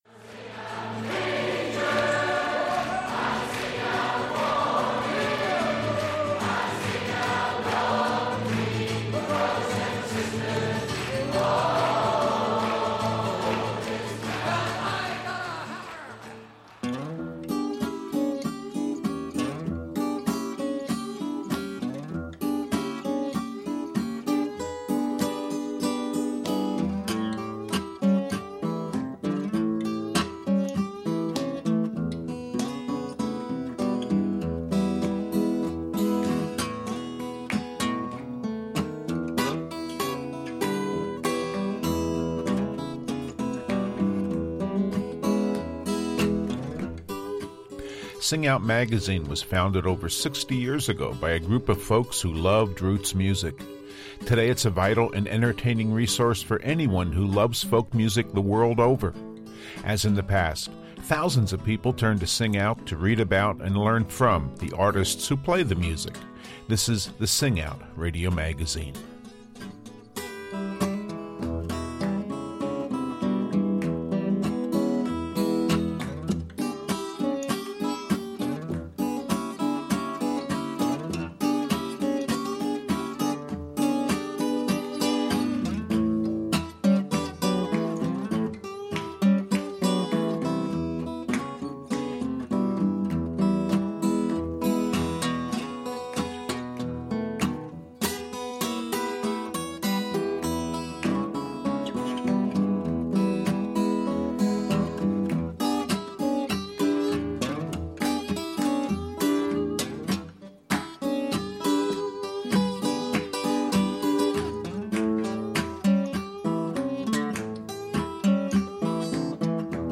On this week’s program, we continue with our occasional feature focusing on the fundamentals of a particular style of music. Piedmont blues is a ragtime inspired music played mostly on the guitar.